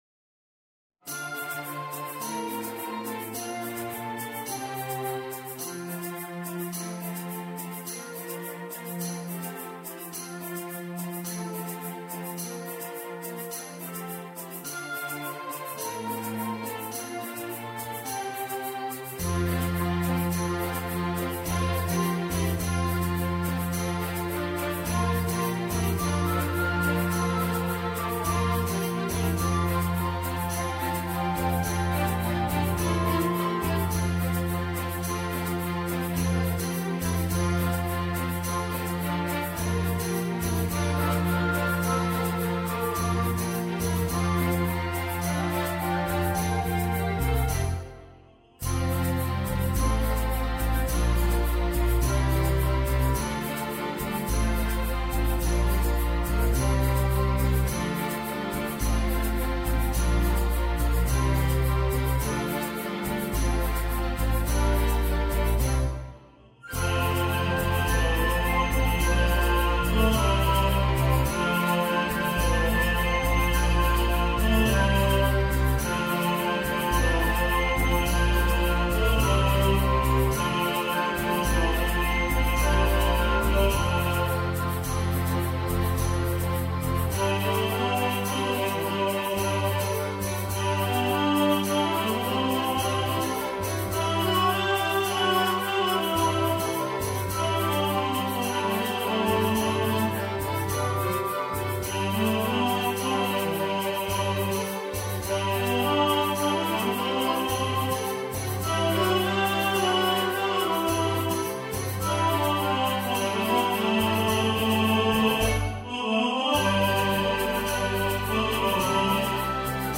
Road Goes On (Tenor) | Ipswich Hospital Community Choir